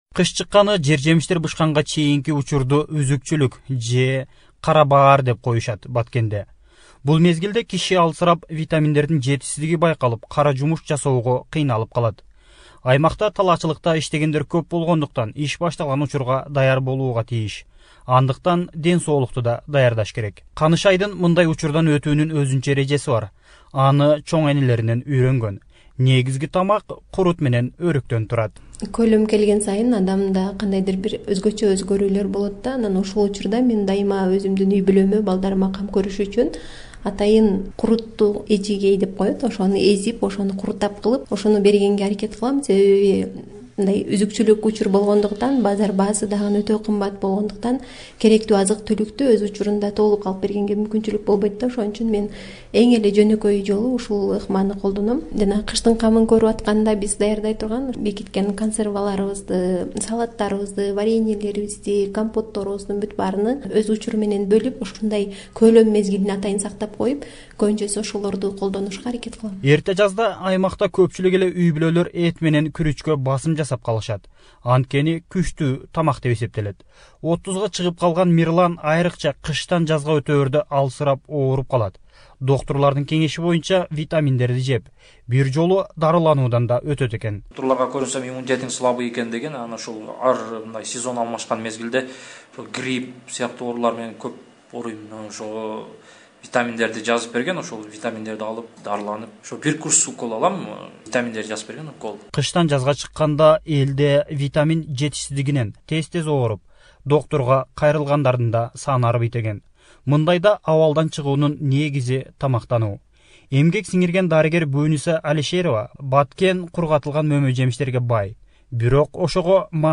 KYR/HEALTH/ Special Radio Package: The local traditions Batken residents which helps maintain the weakened body in the spring